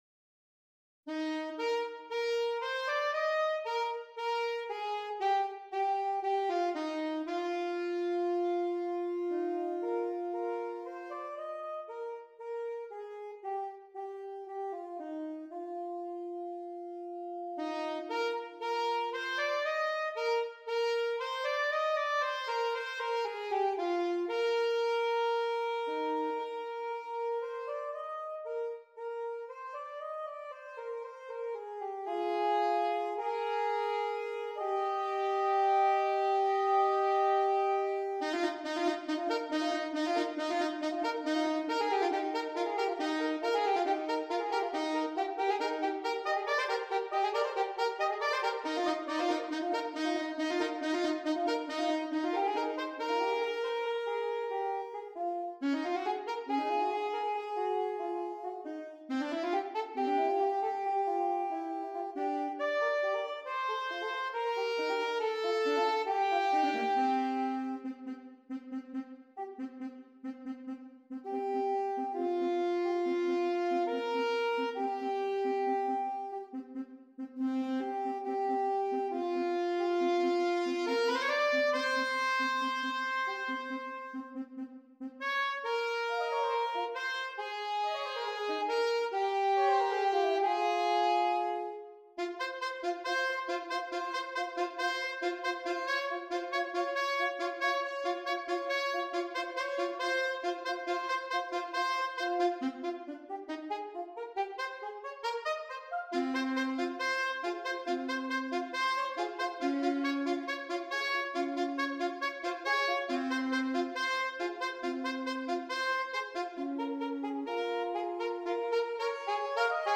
2 Alto Saxophones